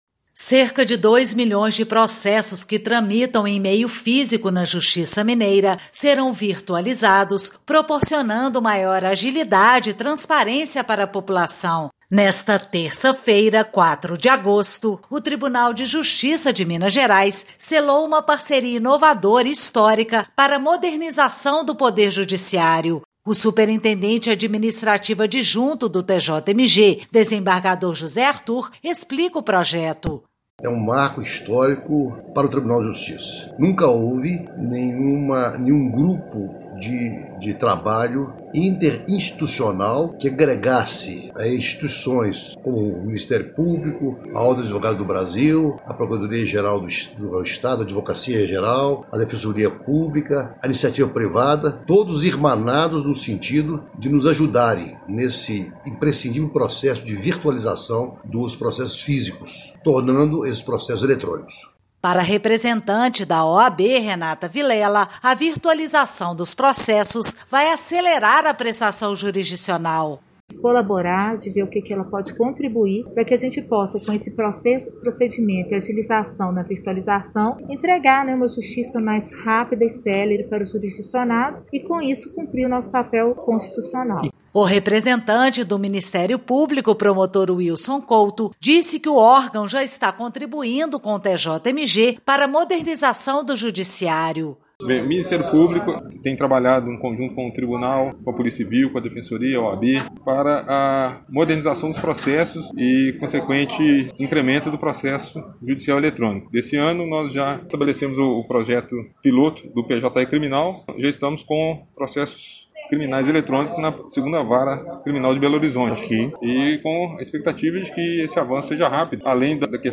Ouça o podcast com os áudios do desembargador José Arthur e de representantes da OAB e MP